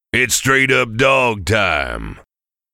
Check out a sneak peek at St. John voicing John Dudebro: